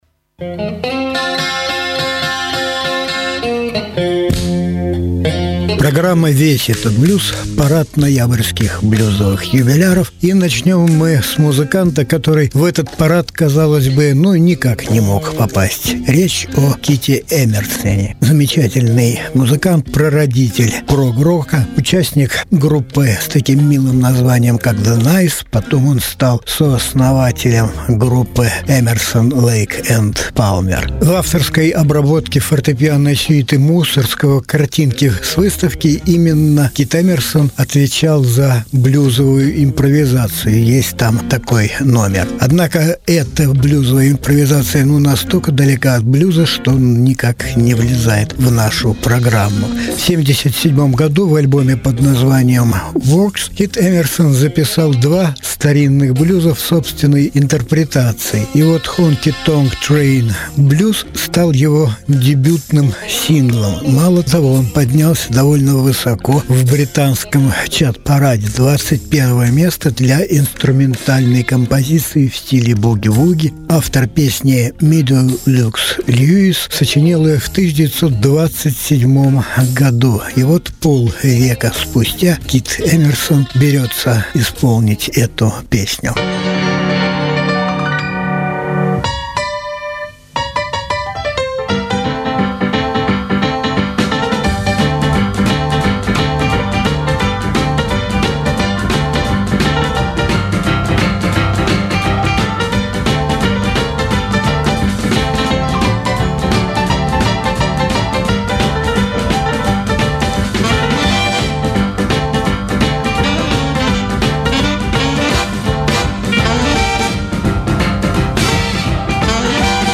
Блюзы и блюзики